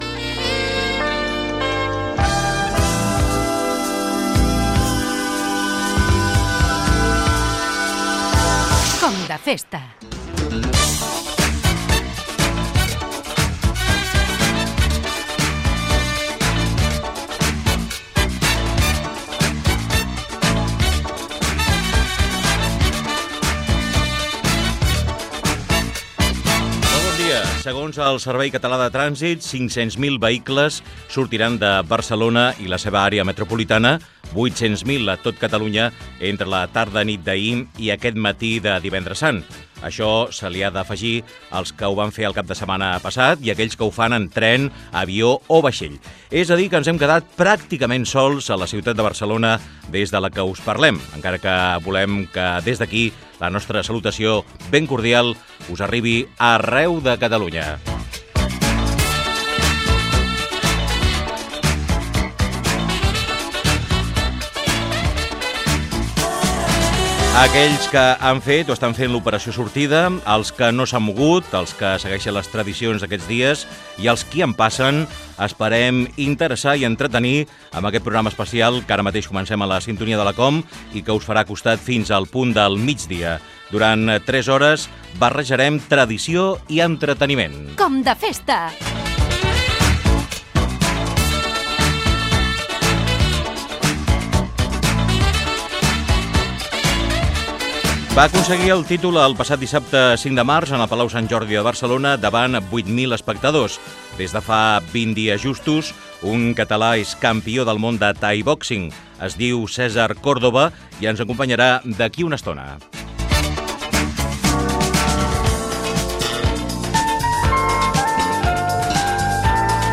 Careta del programa, dades del trànsit en el divendres sant. Presentació.
Entreteniment